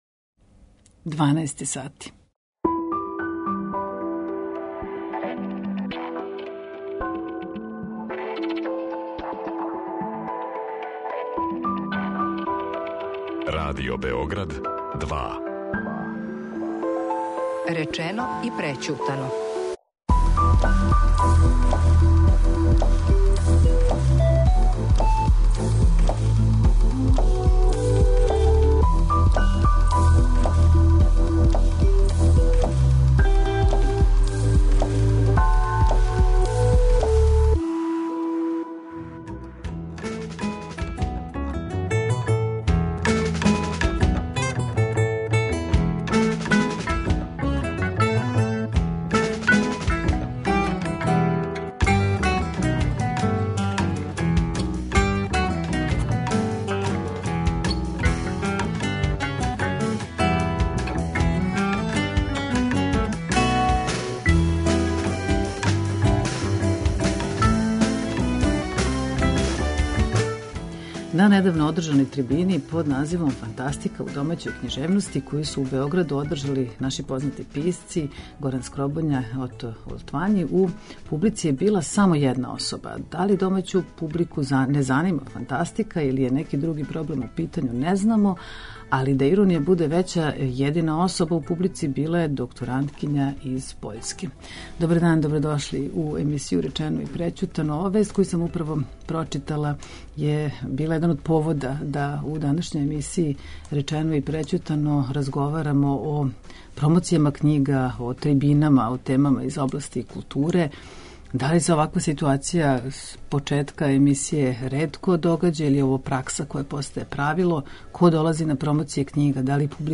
Трибине о темама из области културе